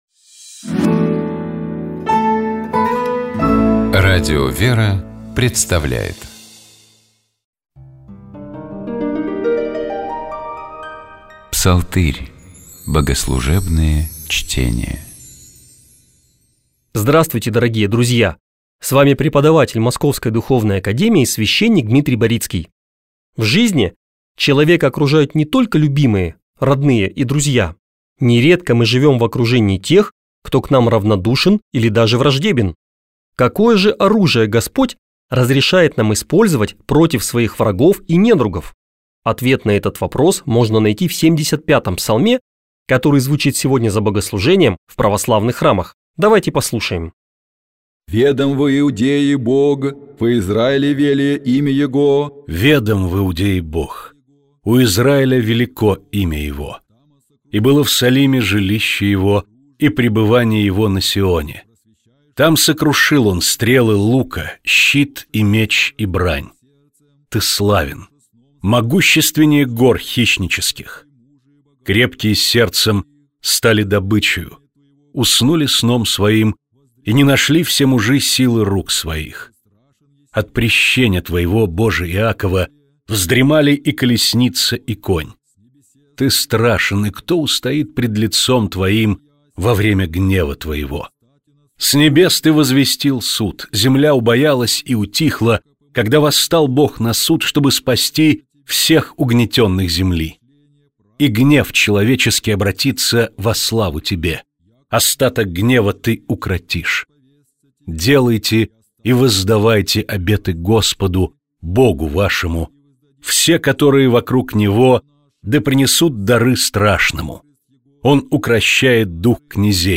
Гостем программы «Исторический час» был доктор исторических наук